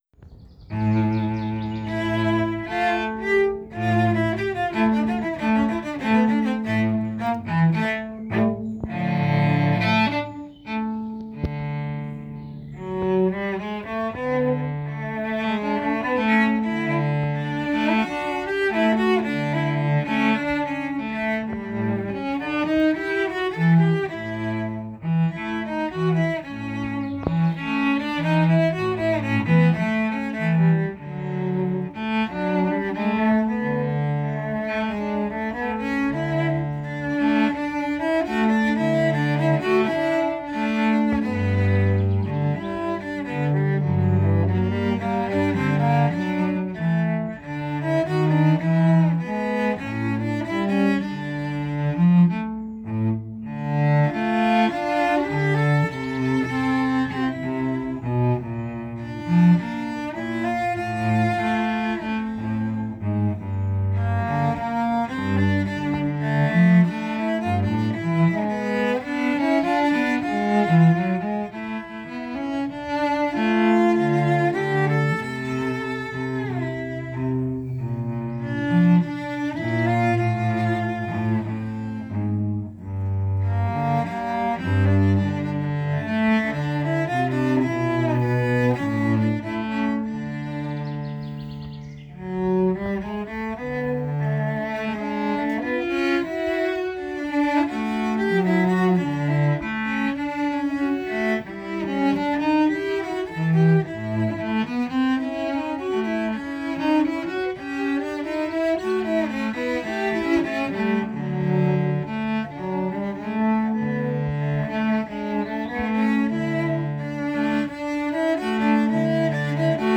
Tango, 1935   Parts: 2 cellos